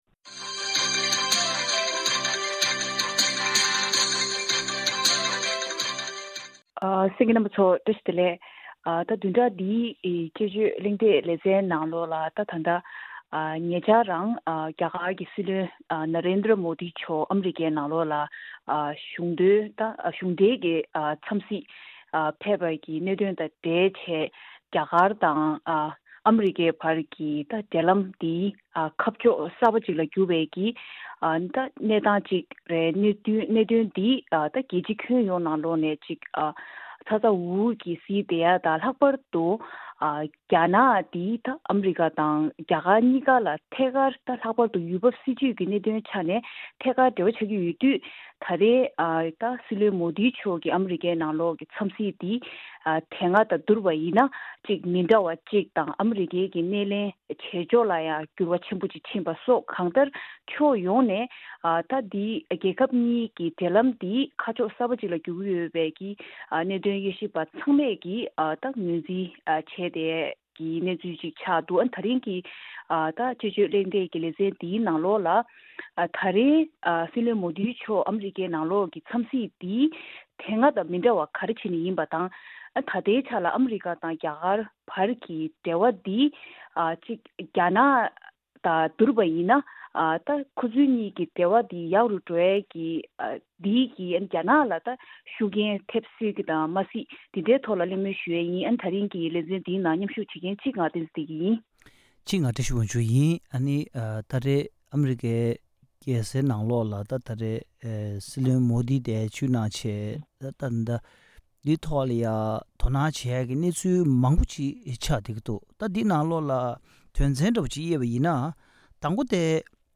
དཔྱད་གཞིའི་གླེང་མོལ་ཞུས་པར་གསན་རོགས་གནང་།